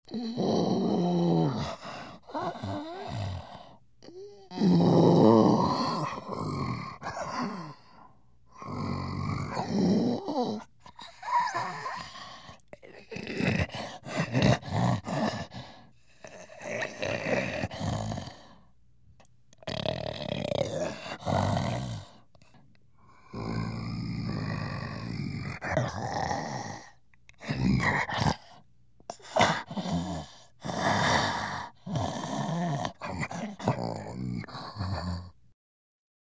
animal
Gorilla Breaths